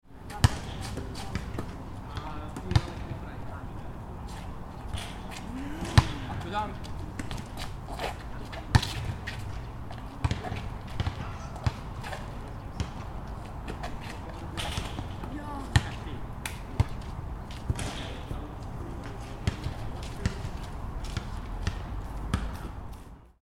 Kids-playing-basketball-on-playground-sound-effect.mp3